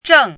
zhèng
zheng4.mp3